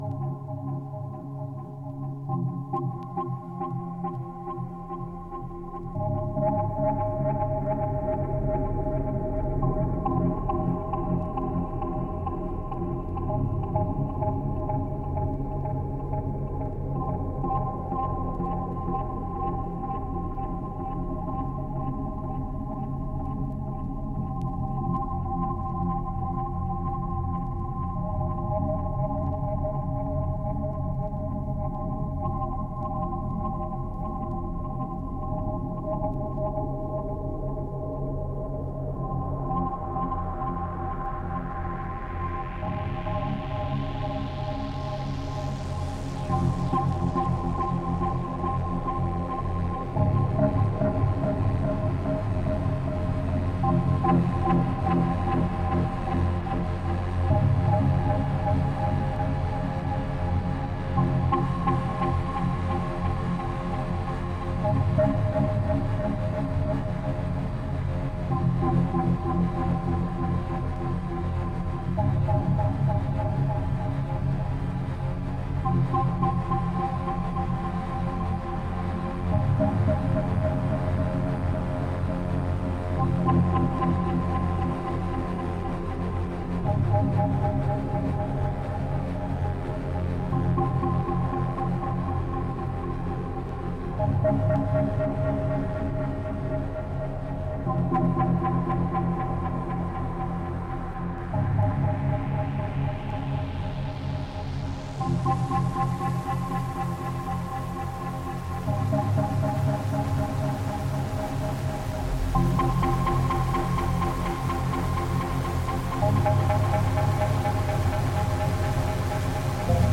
Rapid Copenhagen techno back in stock.